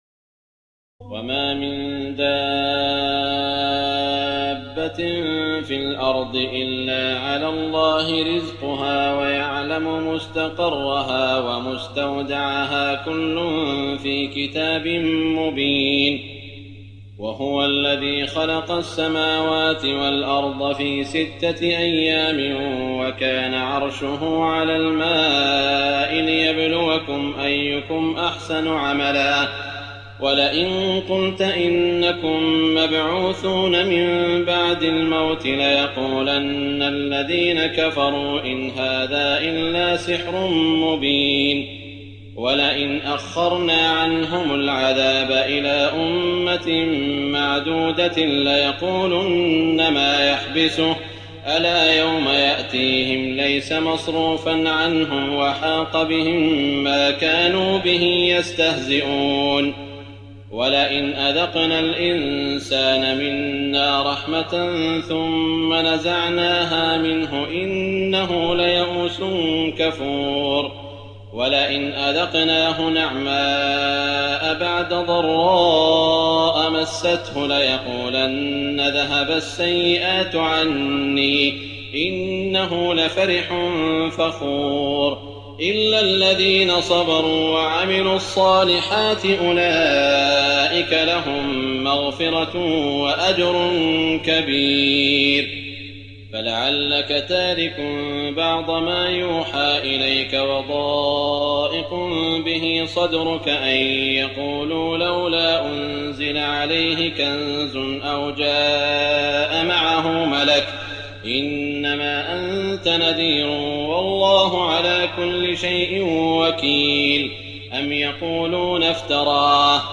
تراويح الليلة الحادية عشر رمضان 1419هـ من سورة هود (6-83) Taraweeh 11 st night Ramadan 1419H from Surah Hud > تراويح الحرم المكي عام 1419 🕋 > التراويح - تلاوات الحرمين